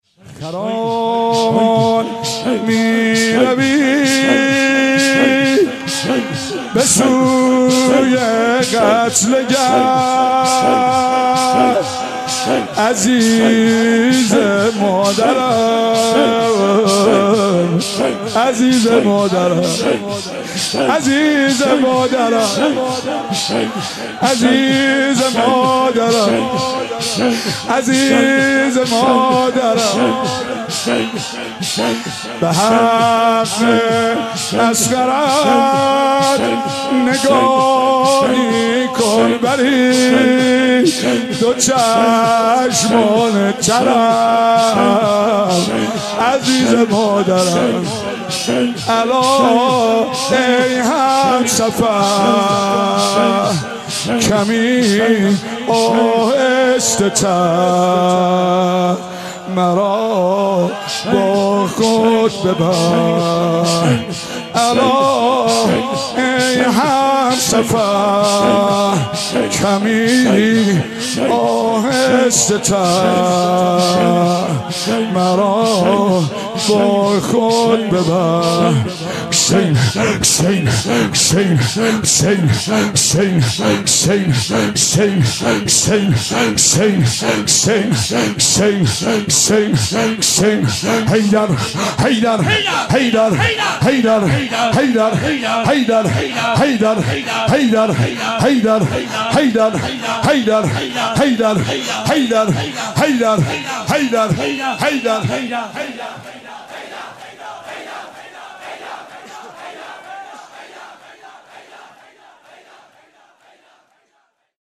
زمزمه شور